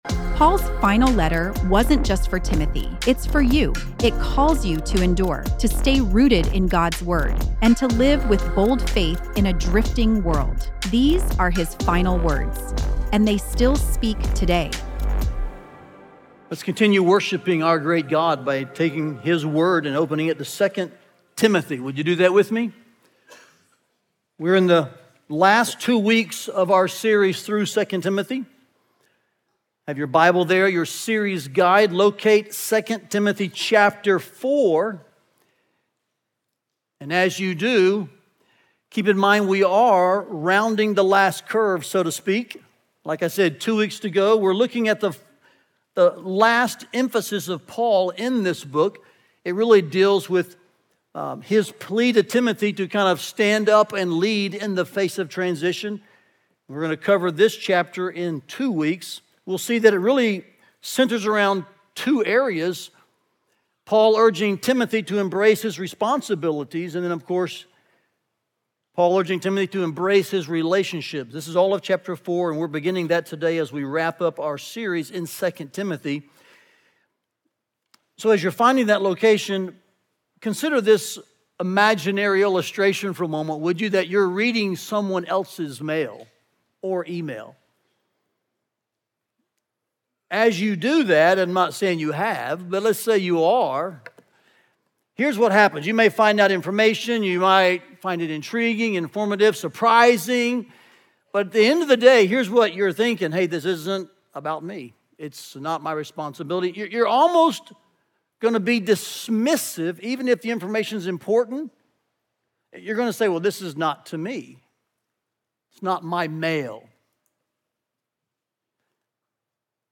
Listen to the latest sermon from our 2 Timothy series, “Final Words”, and learn more about the series here.